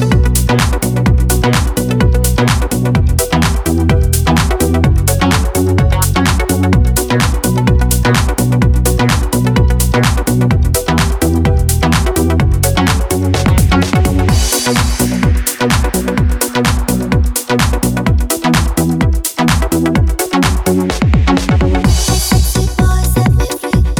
Duet Version Pop